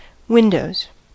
Ääntäminen
Ääntäminen RP : IPA : /ˈwɪndəʊz/ US : IPA : [wɪn.dəʊz] GenAm: IPA : /ˈwɪndoʊz/ Haettu sana löytyi näillä lähdekielillä: englanti Käännöksiä ei löytynyt valitulle kohdekielelle.